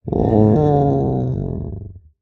Minecraft Version Minecraft Version snapshot Latest Release | Latest Snapshot snapshot / assets / minecraft / sounds / mob / sniffer / idle9.ogg Compare With Compare With Latest Release | Latest Snapshot